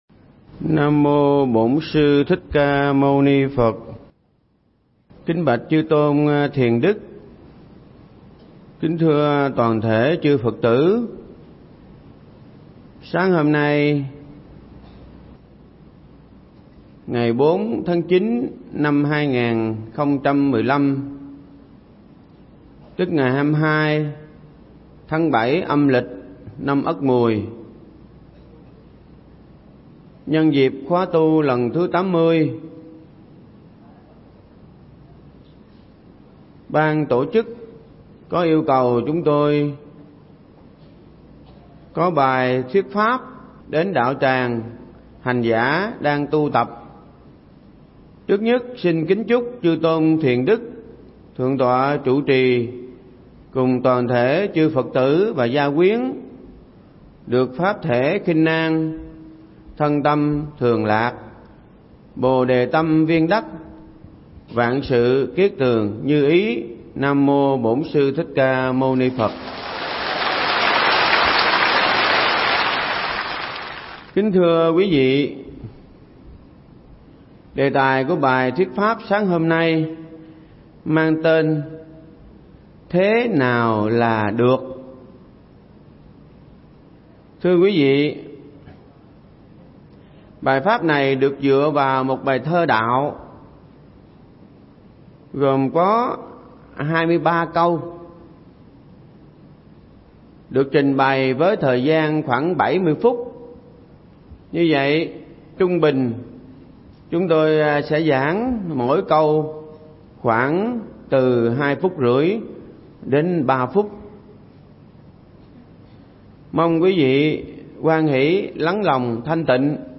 Mp3 Pháp Thoại Sống Một Kiếp Người Bình An Là Được
giảng tại Tu Viện Tường Vân trong khóa tu Một Ngày An Lạc lần thứ 32